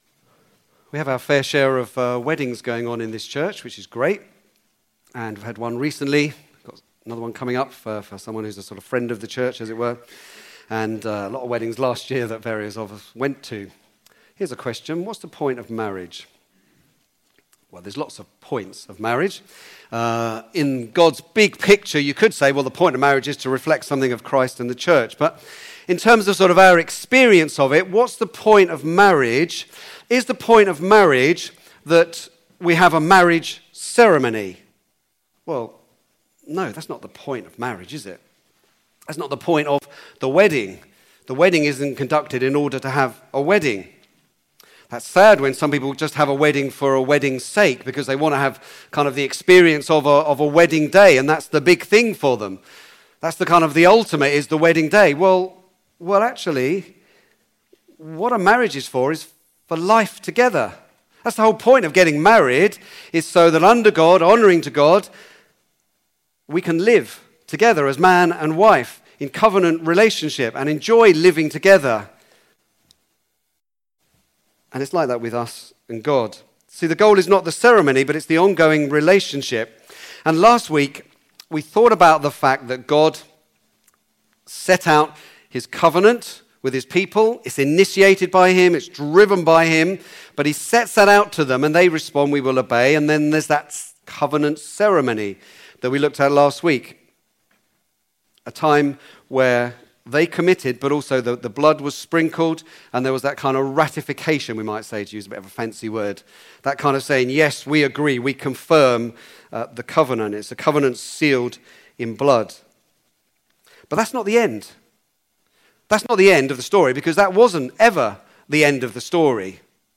Back to Sermons The way to meet with God